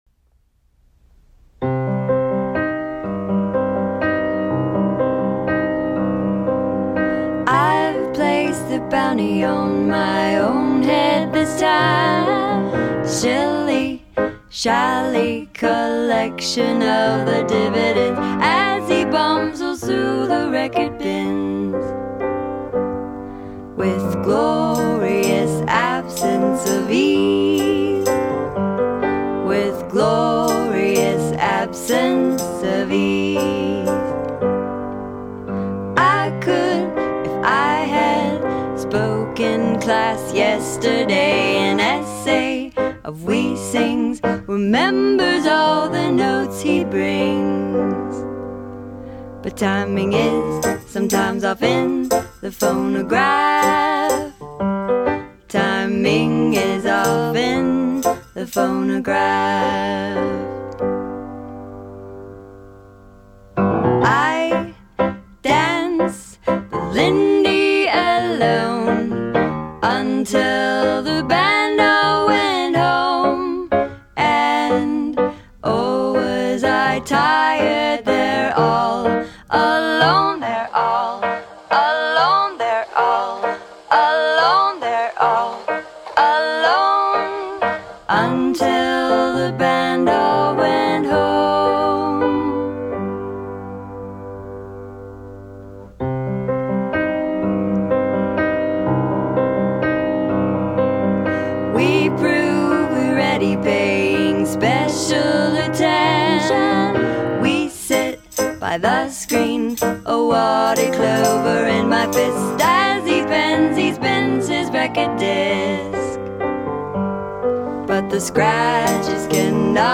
having recently been committed to warm analog tape
Piano / Vocals
Guitar